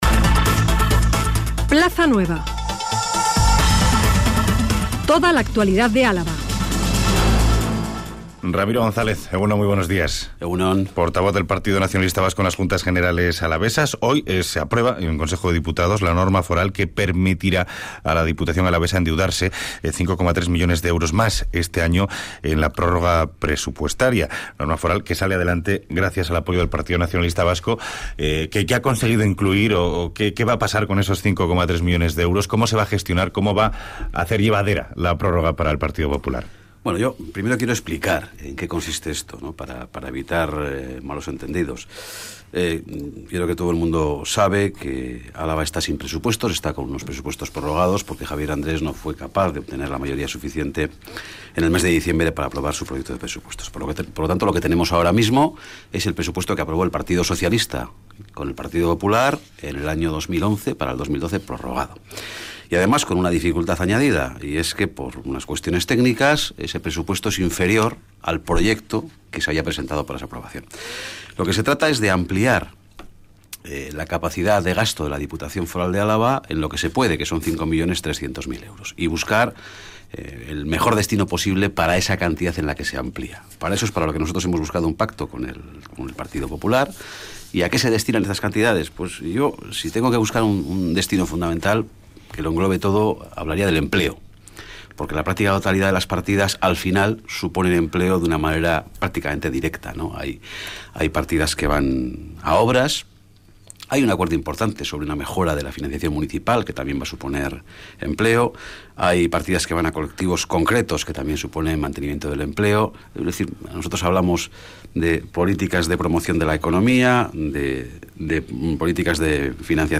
Entrevista a Ramiro González, portavoz del PNV en JJGG de Álava